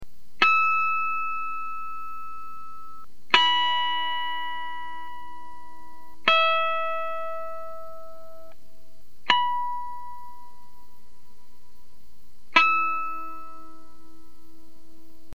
In sequence, starting with the fifth fret, the pitch harmonics sound as follows:
They range from high pitch at the fifth, to the lowest pitch at the twelfth fret, and then climb in pitch again.